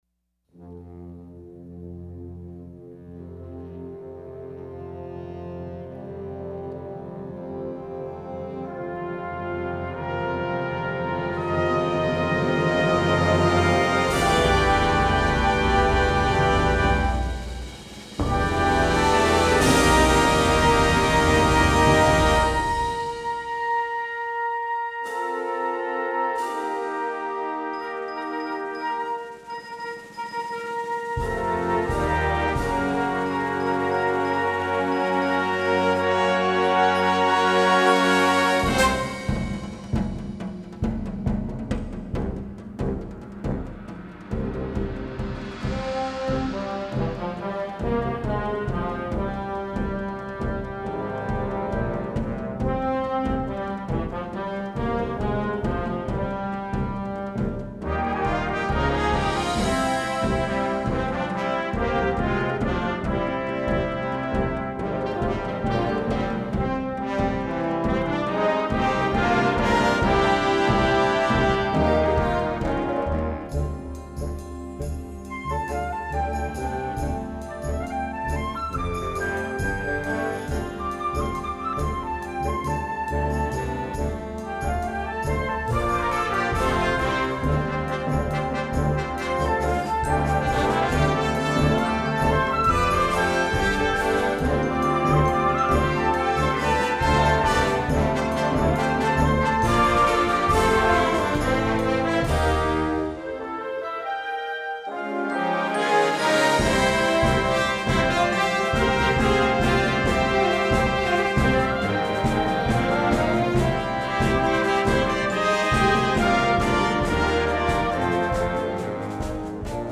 Répertoire pour Harmonie/fanfare - Concert Band